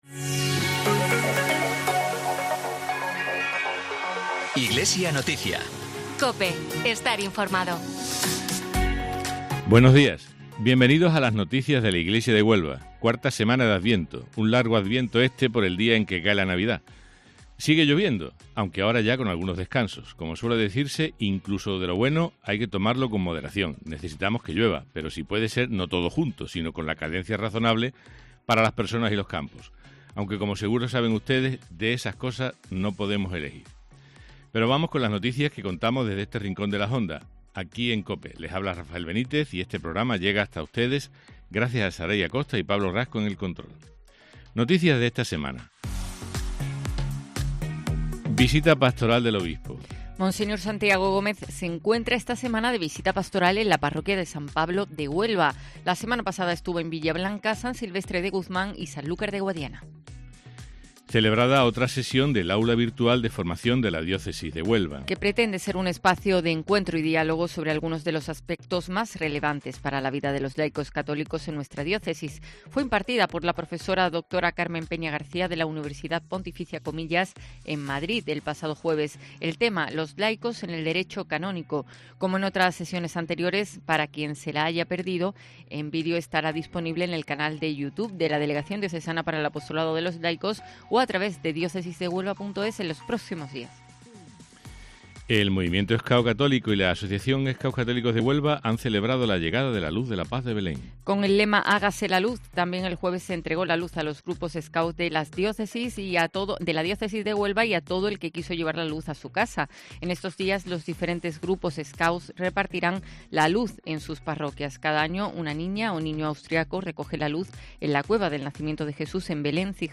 Con la llegada del cuarto domingo de adviento, en la inminencia de la Navidad, todo gira en torno a ese acontecimiento, todos se preparan, se multiplican actos solidarios y nos llega la Luz de la Paz de Belén gracias al movimiento scout. Entrevistamos